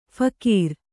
♪ phakīr